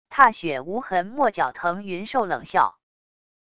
踏雪无痕墨角腾云兽冷笑wav音频